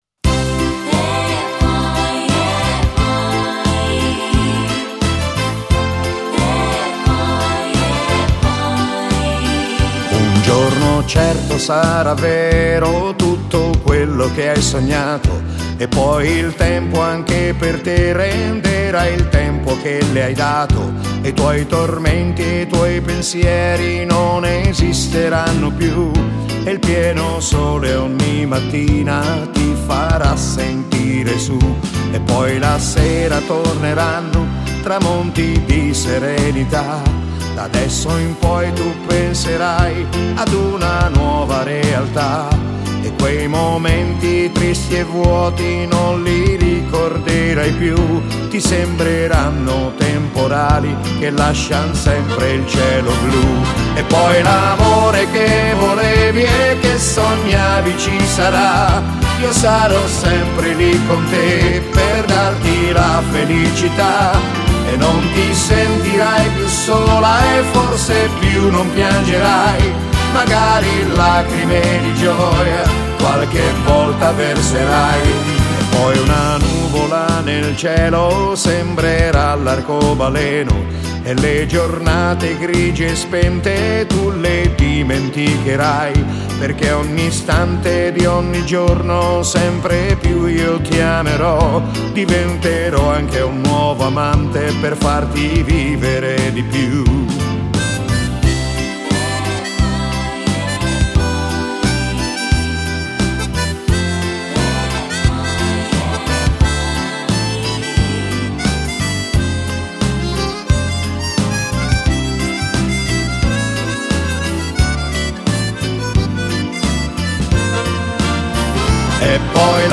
Genere: Fox trot